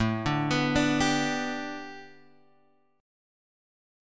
A 7th Suspended 2nd Suspended 4th